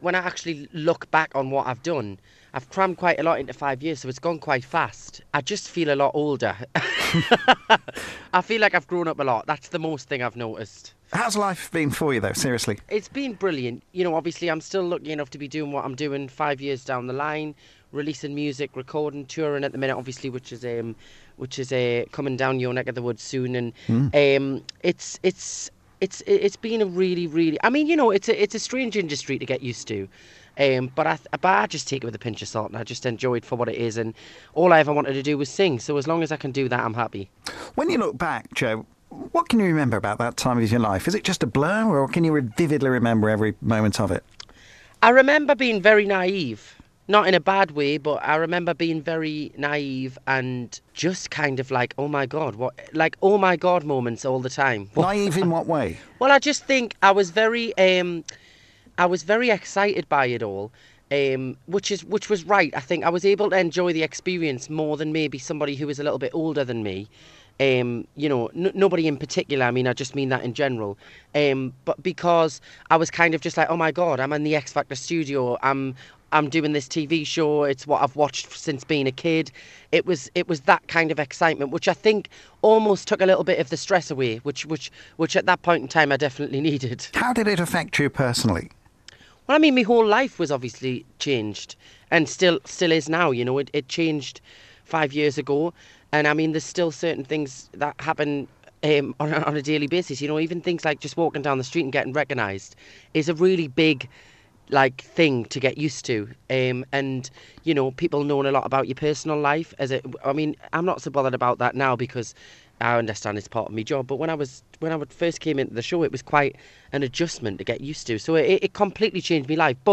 Joe chatting to me about life after The X Factor.